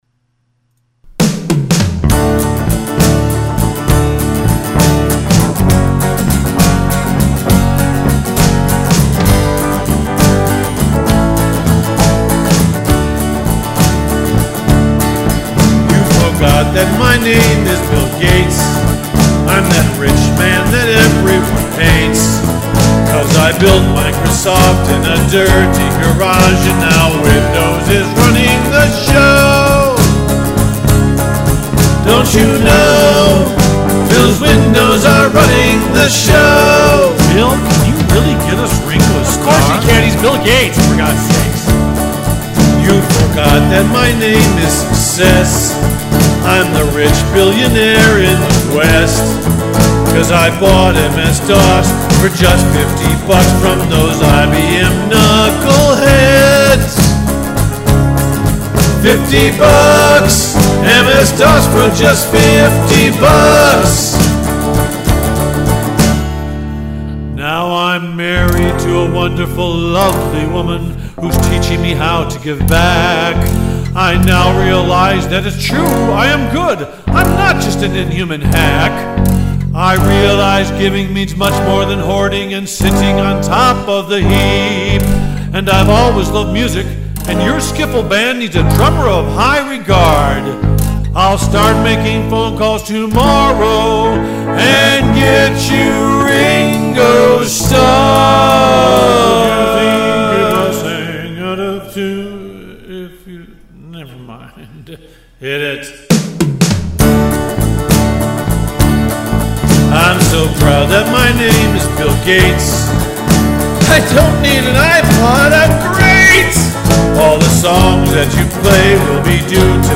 Musical Play
horn solos